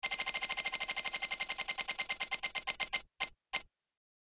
Download Free Leisure Sound Effects | Gfx Sounds
Fortune-wheel-spinning-3.mp3